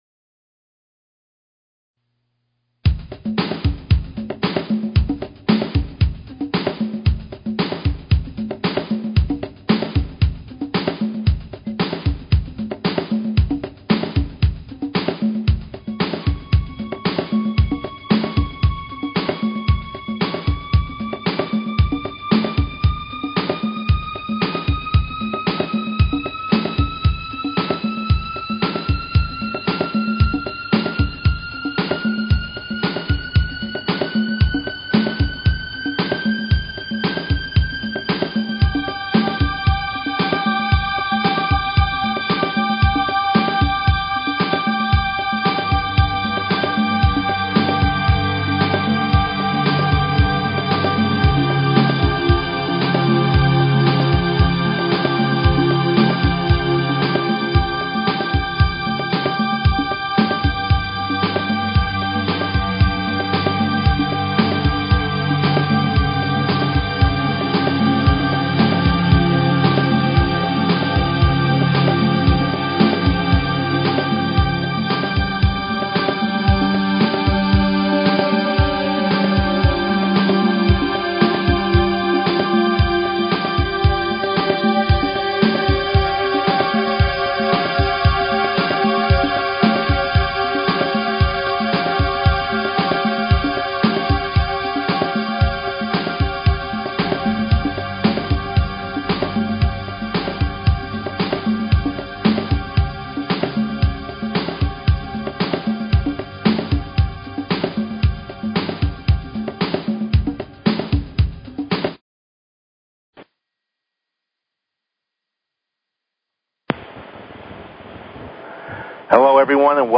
Talk Show Episode, Audio Podcast, Your_Inner_Thoughts and Courtesy of BBS Radio on , show guests , about , categorized as